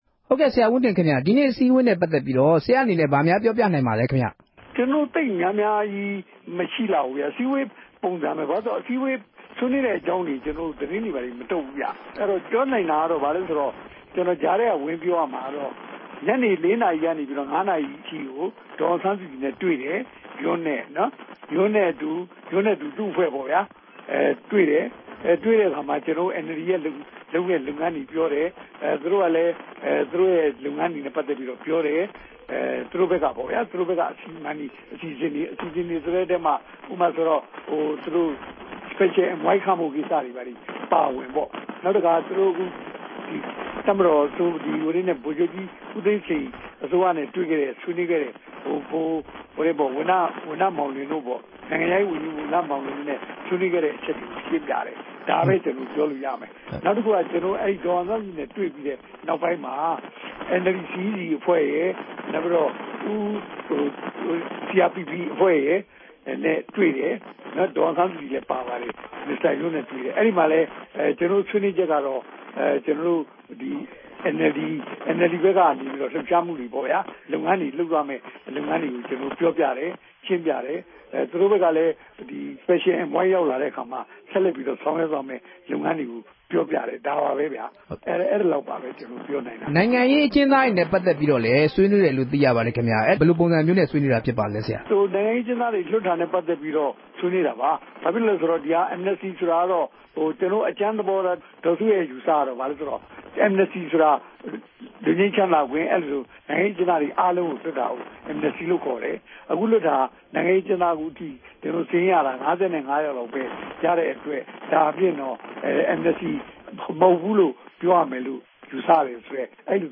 သတင်းစာရှင်းပွဲမှာ ရှိနေတဲ့ ဂျာနယ် သတင်းထောက် တဦးက အခုလို ပြောပြပါတယ်။